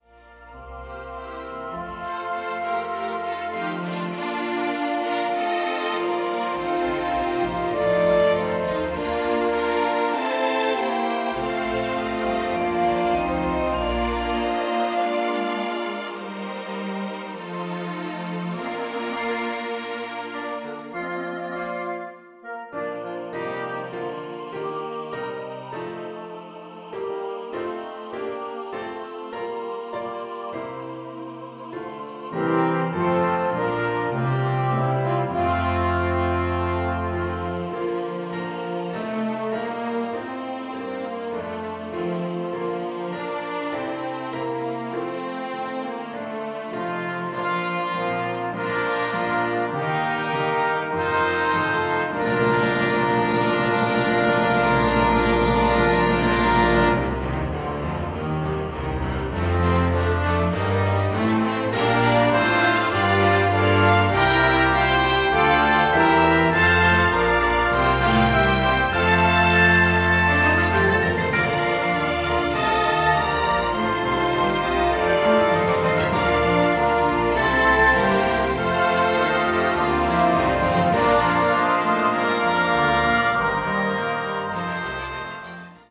SATB
2 Flutes
Oboe
2 Clarinets
Bassoon
3 Horns
3 Trumpets
Tuba
Timpani
Percussion (Tubular Bells, Bass Drum, Cymbals, Glockenspiel)
Piano
Cello
Double Bass